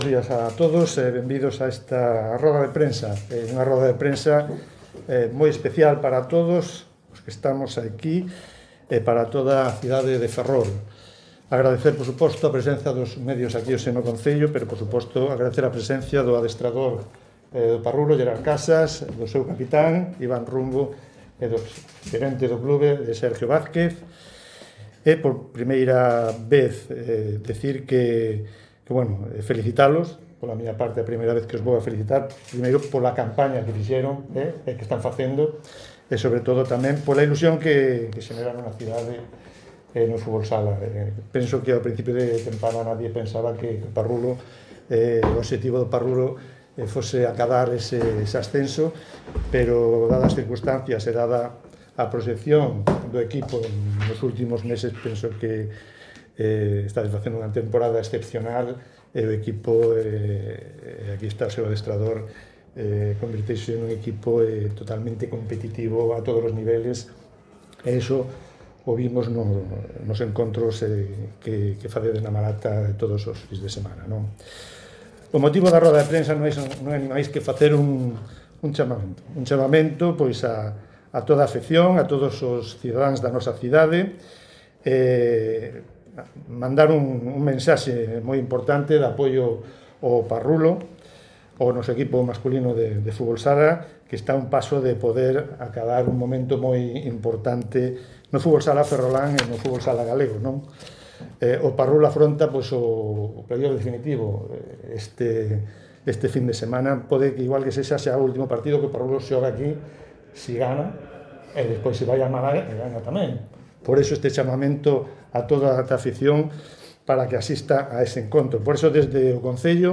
durante la rueda de prensa celebrada en el edificio consistorial.